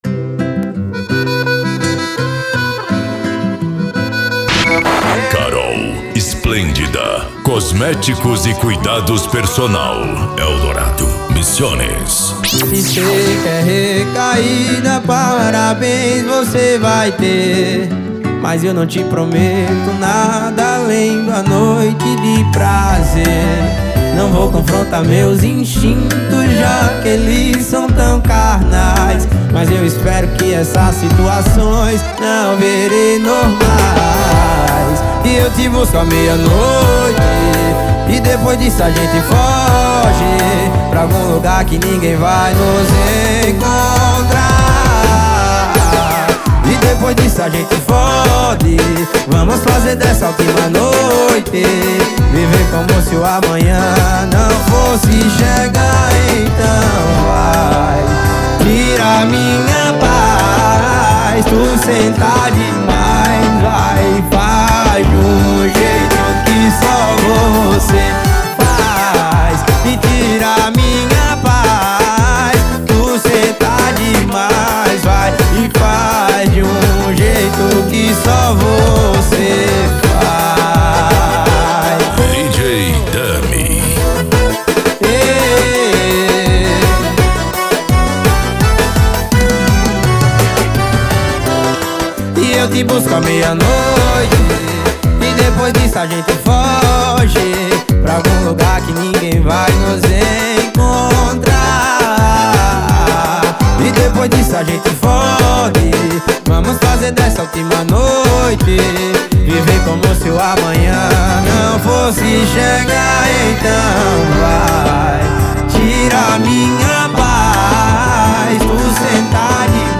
Arrocha
Funk
Reggaeton